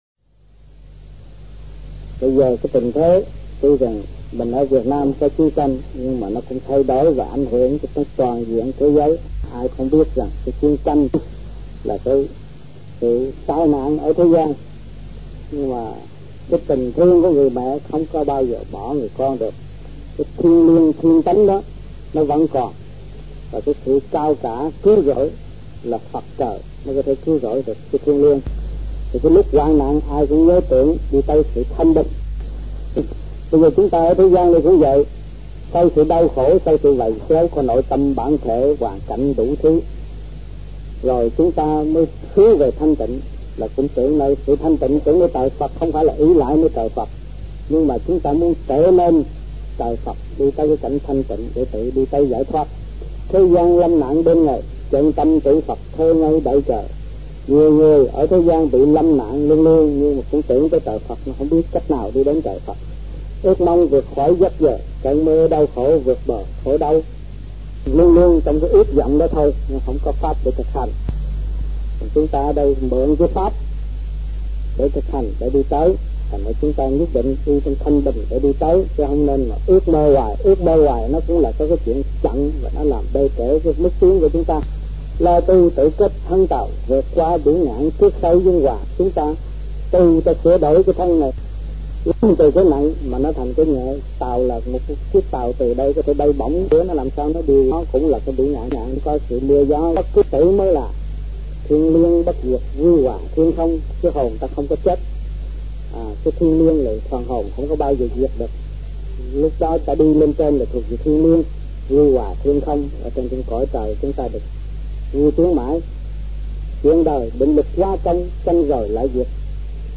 Việt Nam Trong dịp : Sinh hoạt thiền đường >> wide display >> Downloads